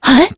Veronica-Vox_Jump.wav